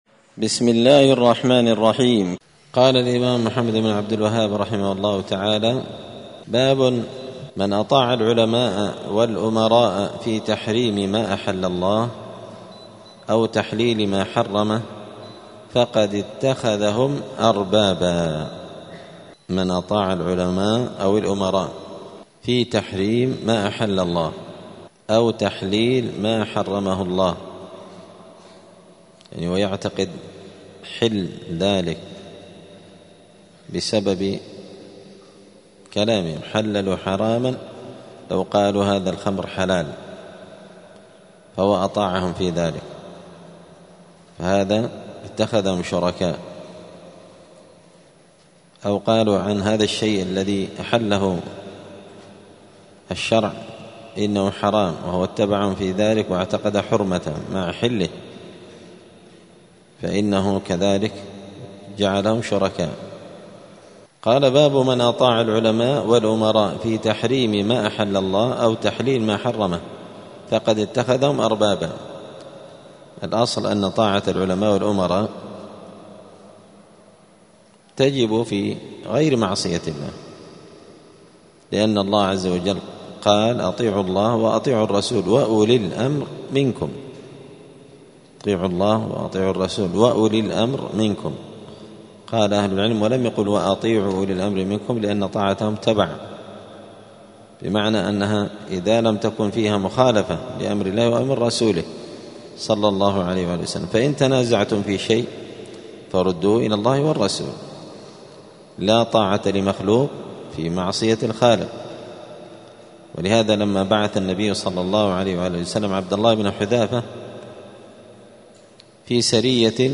دار الحديث السلفية بمسجد الفرقان قشن المهرة اليمن
الأربعاء 18 جمادى الأولى 1446 هــــ | الدروس، حاشية كتاب التوحيد لابن قاسم الحنبلي، دروس التوحيد و العقيدة | شارك بتعليقك | 38 المشاهدات